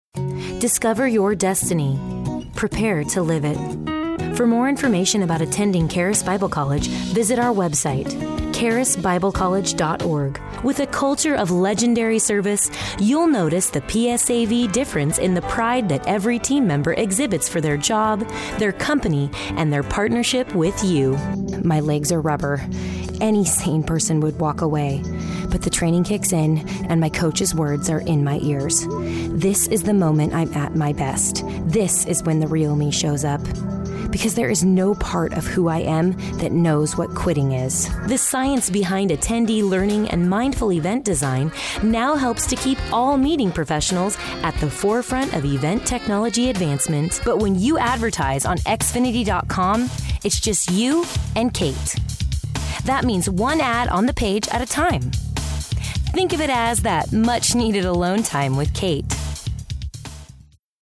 I am hip, modern, youthful and a ton of fun!